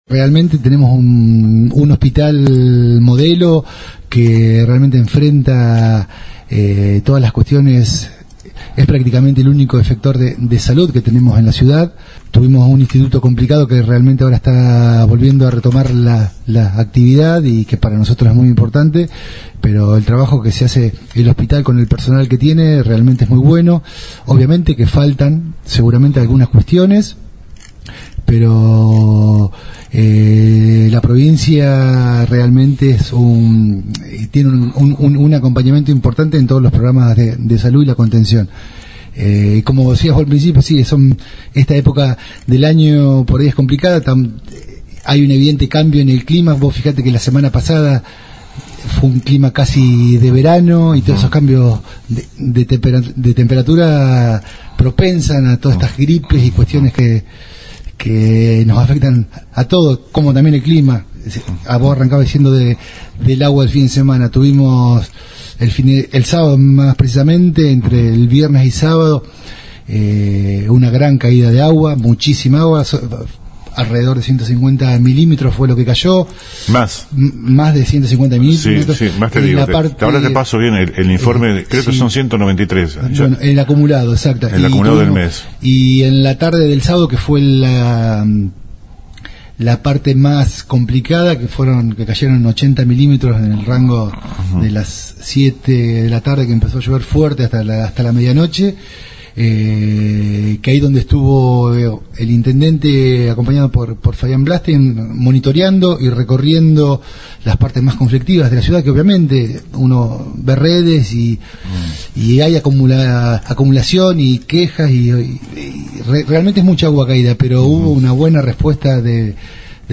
Audio completo de la entrevista a Martín Boggini.